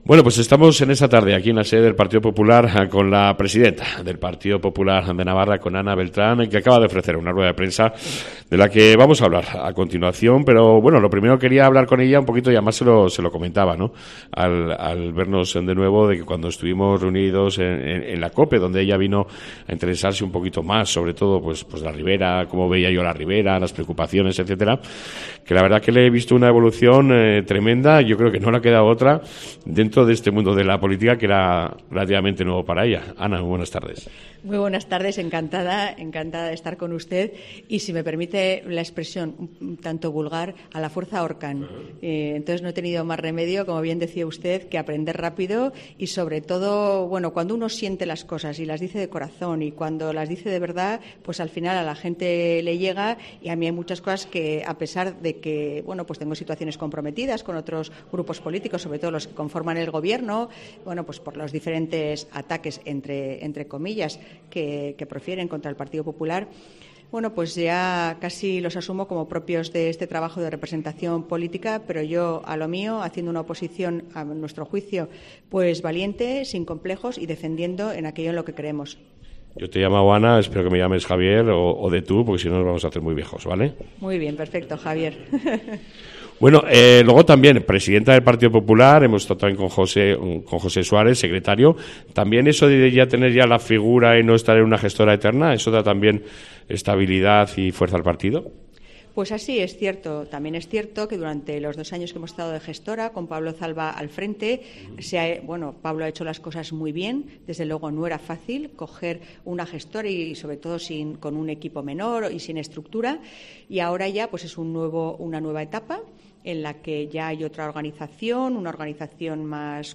ENTREVISTA CON LA PRESIDENTA DEL PARTIDO POPULAR DE NAVARRA , ANA BELTRÁN, EN COPE RIBERA.